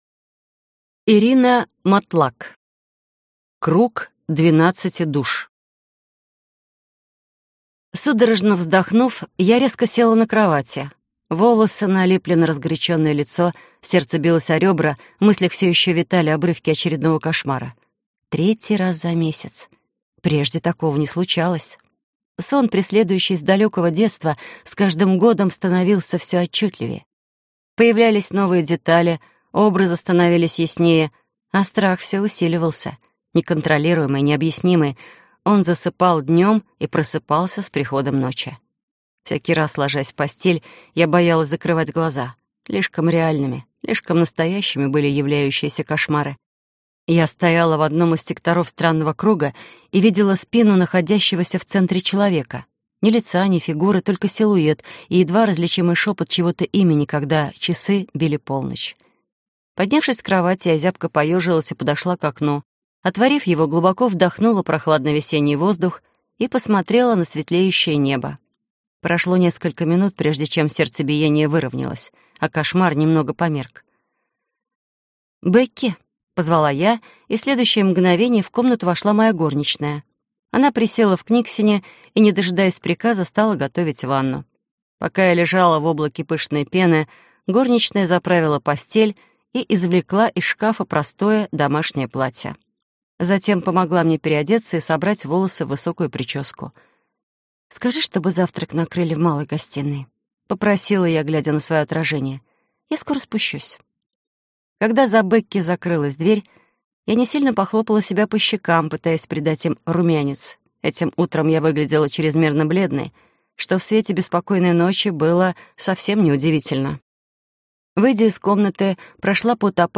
Аудиокнига Круг двенадцати душ - купить, скачать и слушать онлайн | КнигоПоиск